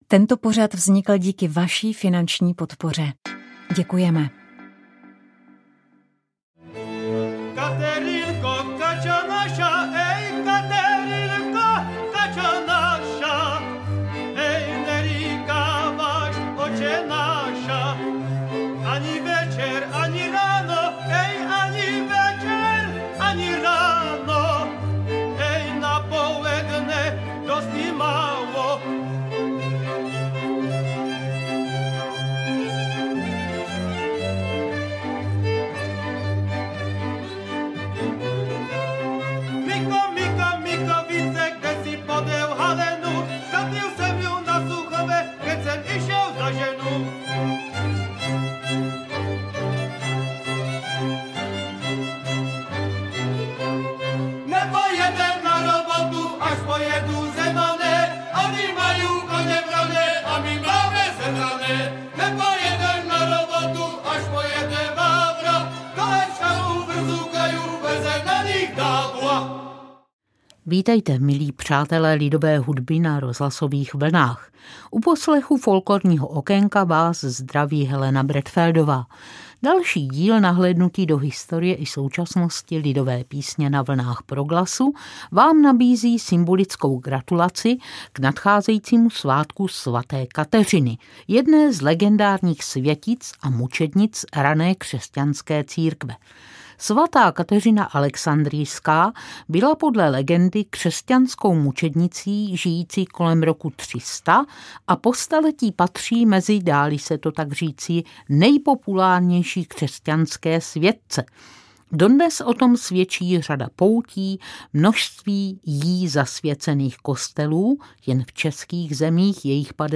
Podoba lidové písně je specifická pro střední Slovensko jak v sólovém zpěvu, tak ve sborových vícehlasech.